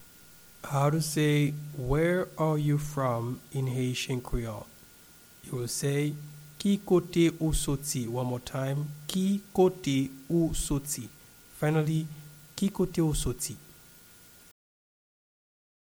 Pronunciation and Transcript:
Where-are-you-from-in-Haitian-Creole-Ki-kote-ou-soti-pronunciation.mp3